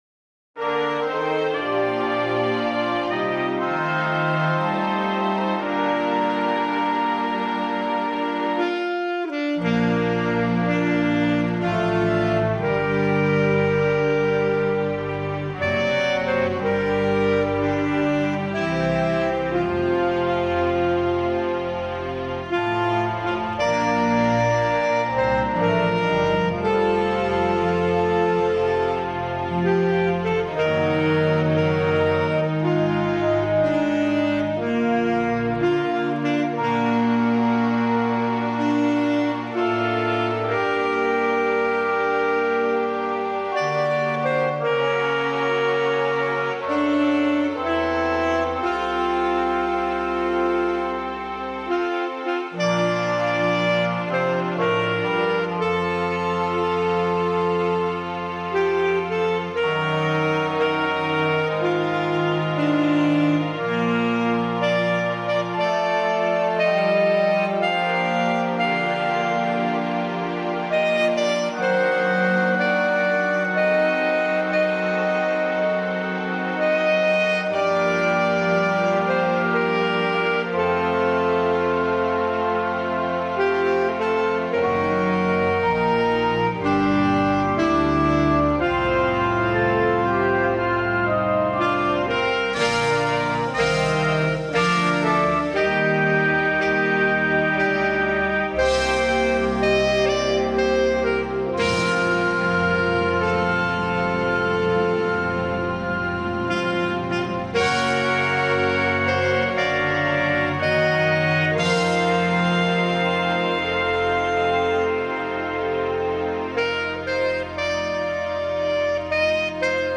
Slow/mid Instrumental Alto sax with orchestral bg